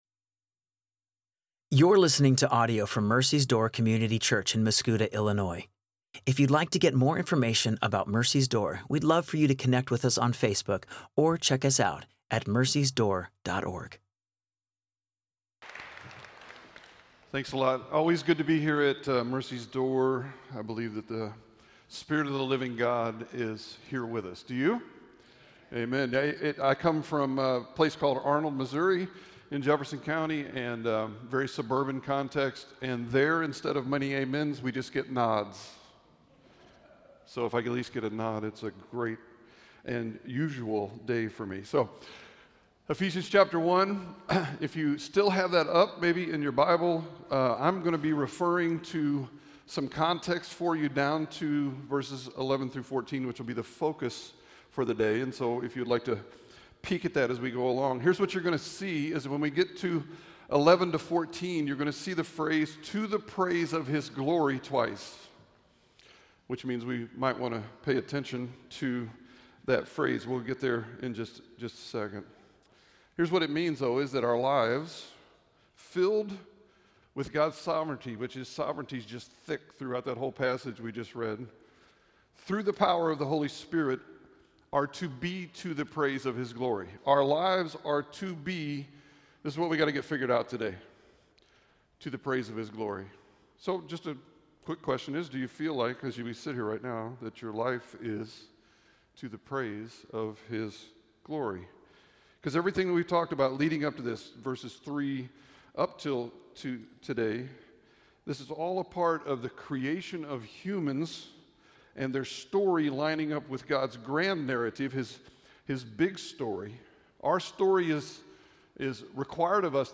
Sermon audio from Mercy's Door Community Church in Mascoutah, IL